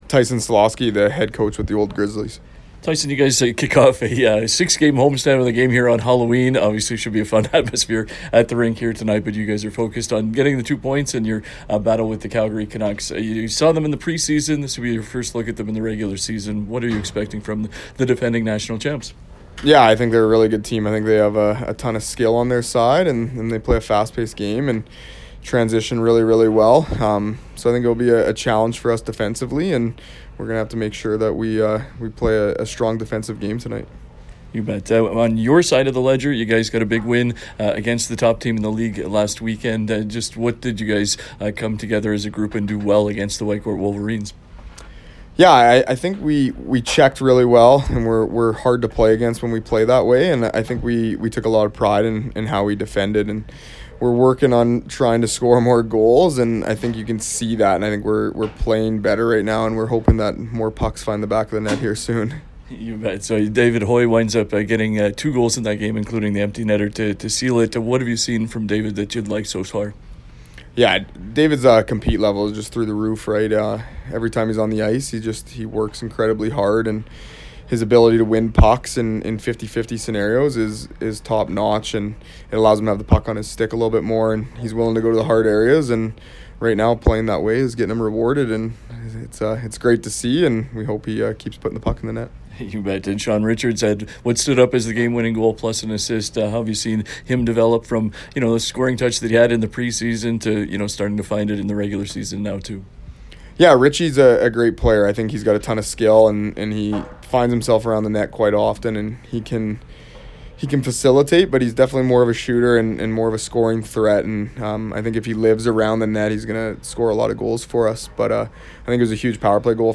pre-game conversation